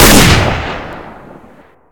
sniper-rifle-gunshot.ogg